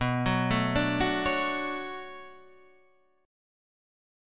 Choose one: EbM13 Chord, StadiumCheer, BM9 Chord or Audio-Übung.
BM9 Chord